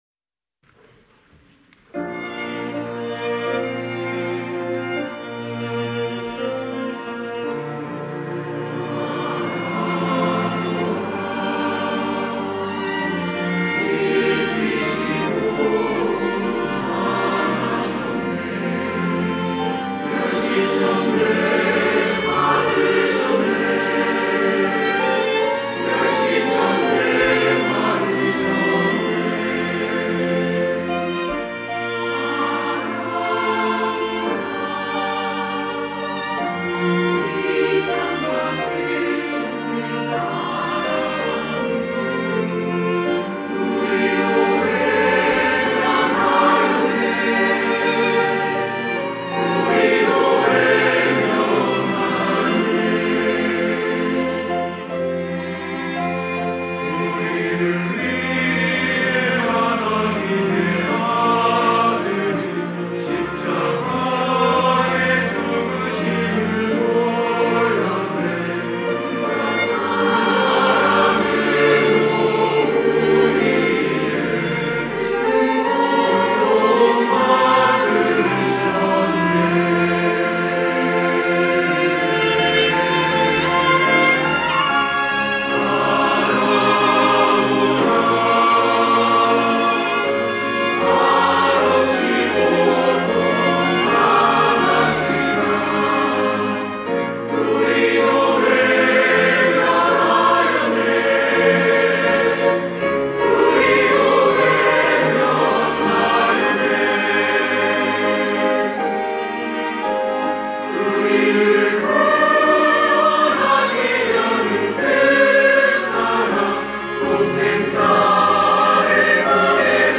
천안중앙교회
찬양대 호산나